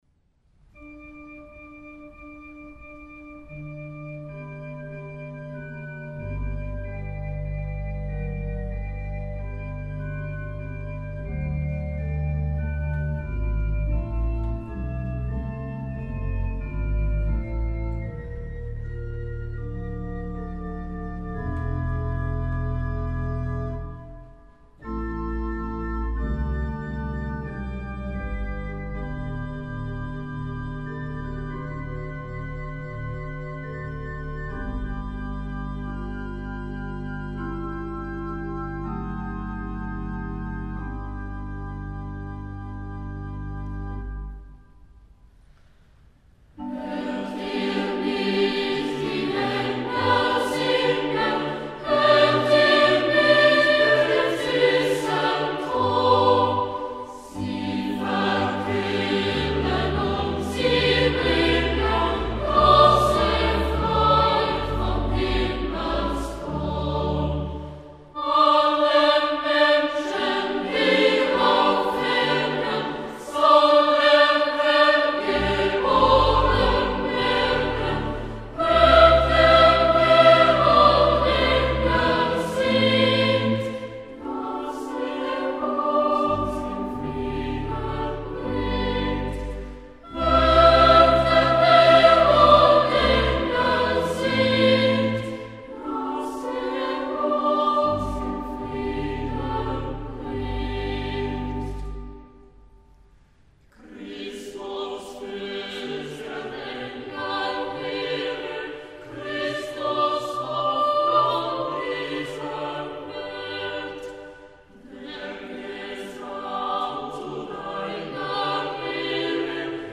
Hört ihr nicht die Engel singen (from Stille Nacht, Heilige Nacht by the Zurich Boys' Choir).
Zurich Boys’ Choir